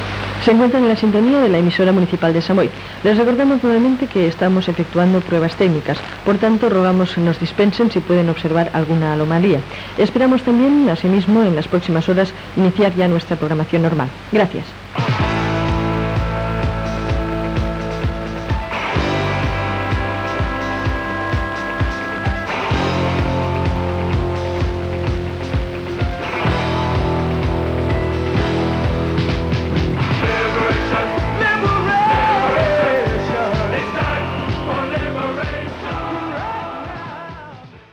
38a0090d68378c805a0631ce4315e64c7078ff6e.mp3 Títol Ràdio Sant Boi Emissora Ràdio Sant Boi Titularitat Pública municipal Descripció Identificació i avís que es realitzen proves tècniques.
Banda FM